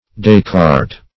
Descartes \Descartes\ (d[asl]*k[aum]rt") prop. n.